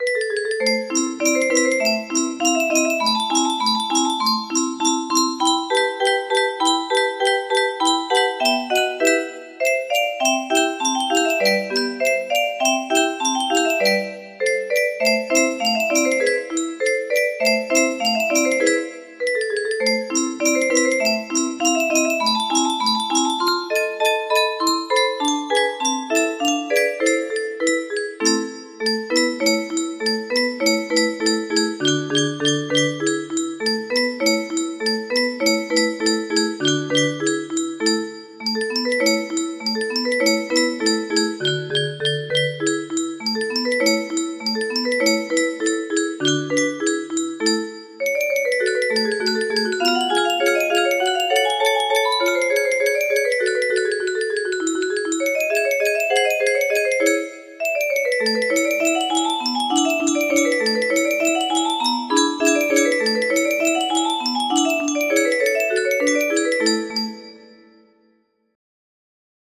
Yay! It looks like this melody can be played offline on a 30 note paper strip music box!